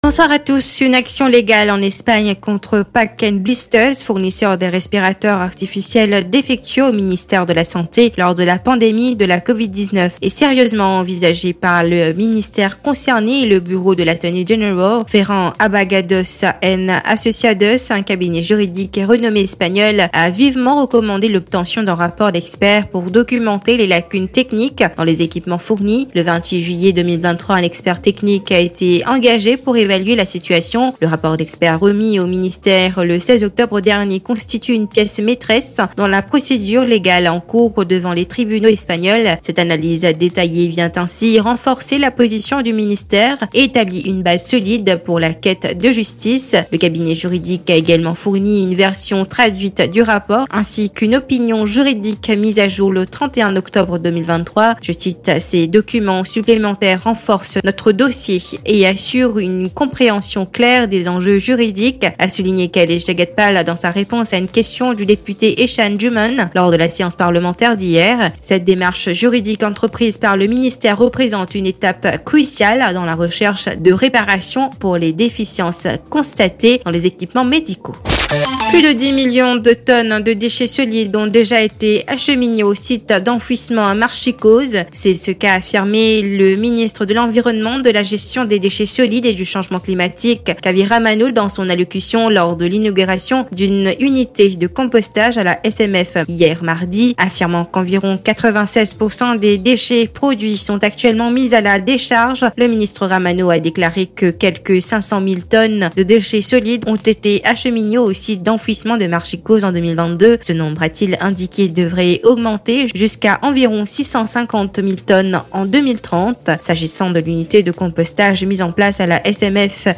NEWS 17H - 15.11.23